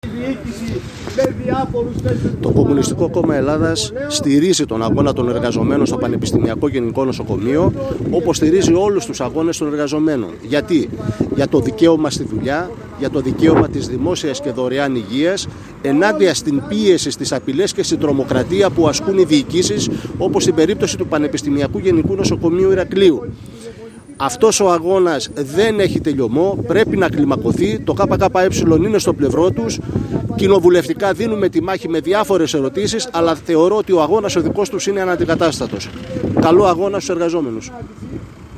οποία χαιρέτισε ο κ. Μανώλης Συντιχάκης Βουλευτής Ηρακλείου του ΚΚΕ.
manolis_syntichakis_voyleytis_irakleioy_toy_kke.mp3